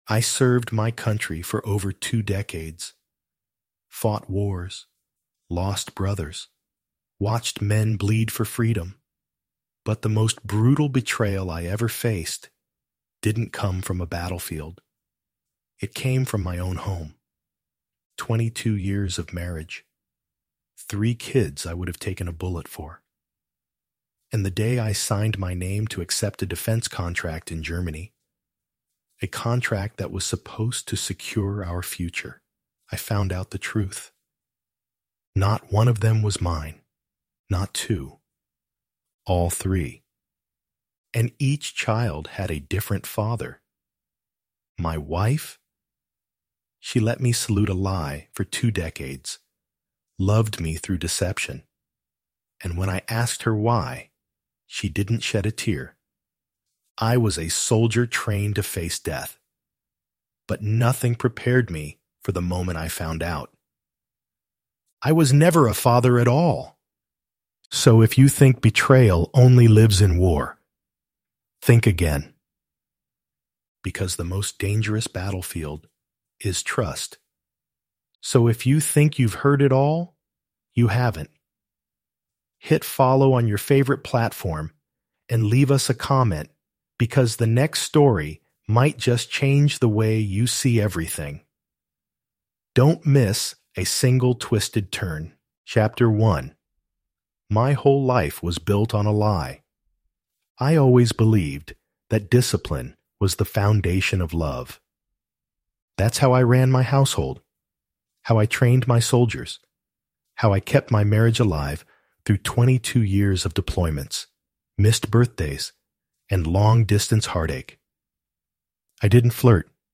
Told in gripping first-person narration, this 7-part cinematic audiobook explores the dark side of loyalty, the collapse of identity, and the true meaning of fatherhood.